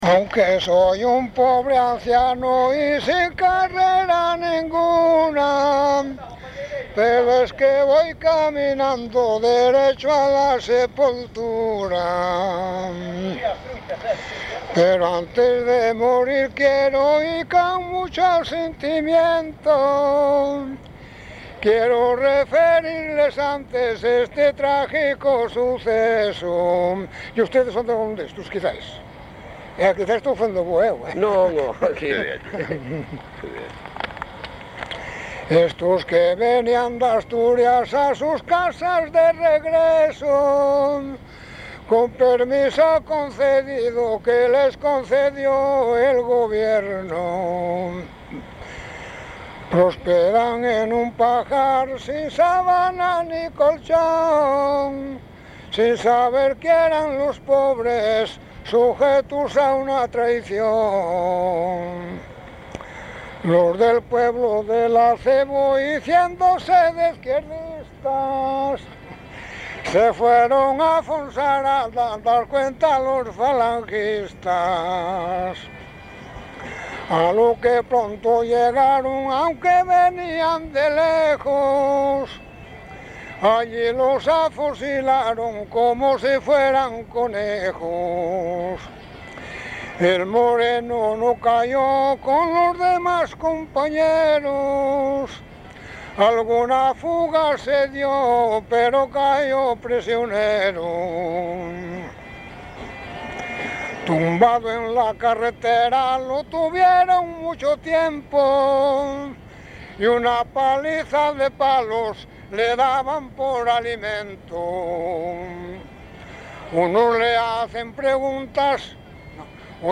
Tipo de rexistro: Musical
LITERATURA E DITOS POPULARES > Cantos narrativos
Instrumentación: Voz
Instrumentos: Voz masculina